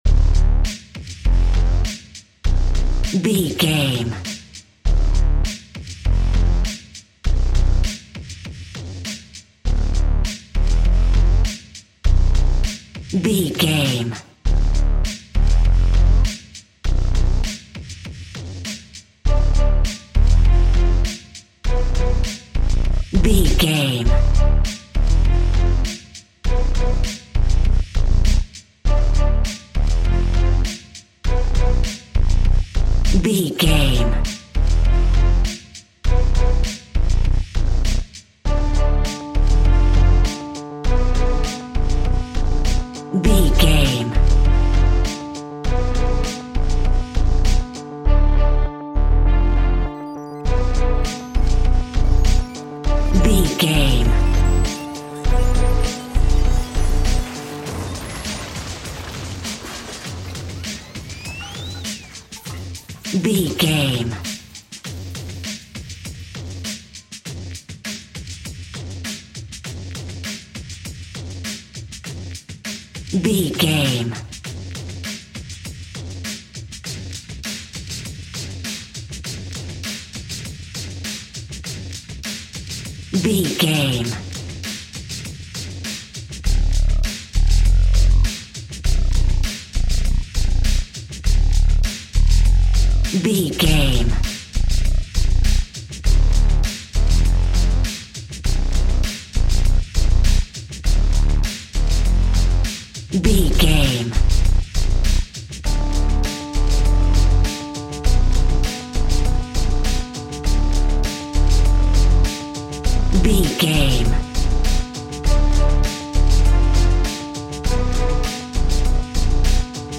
Aeolian/Minor
D
aggressive
powerful
dark
groovy
smooth
futuristic
industrial
drum machine
synthesiser
strings
percussion
Funk
hip hop
electronic